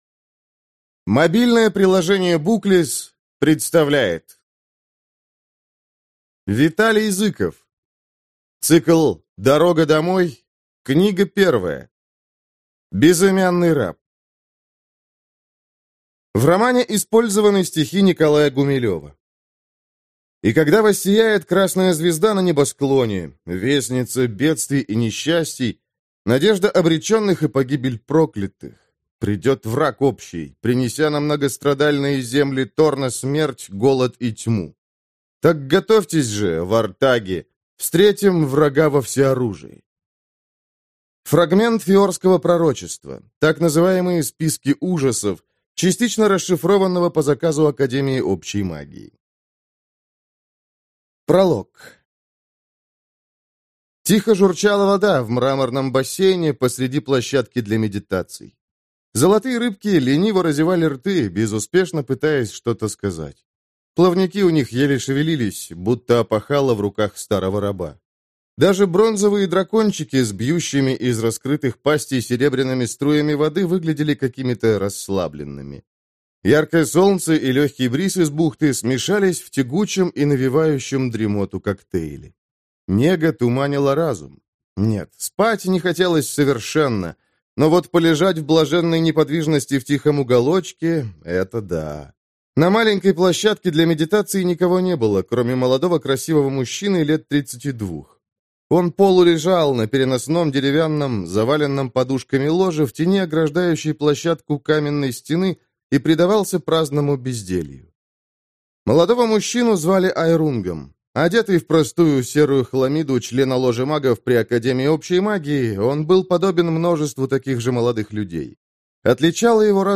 Аудиокнига Безымянный раб - купить, скачать и слушать онлайн | КнигоПоиск